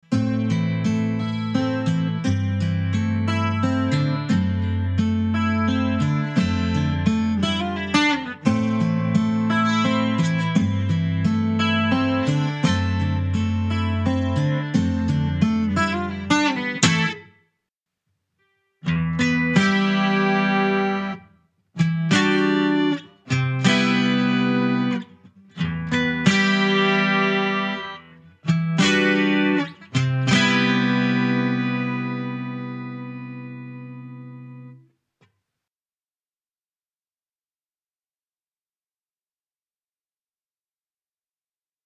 Pour ce test, j’ai simplement enregistré un arpège et une rythmique de chaque acoustique modélisée avant et après la mise à jour.
L’équalization a été légèrement modifée pour certaines guitare mais surtout, la définition du son est meilleure, les guitares ont plus de corps et chacune des cordes des 12 cordes semblent plus perceptibles et réalistes.
2-Guild-F212-New.mp3